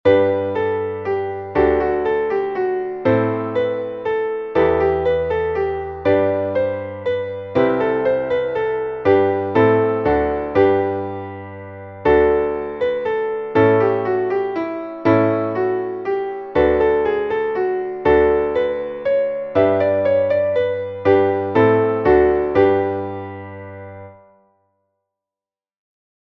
Traditionelles Weihnachtslied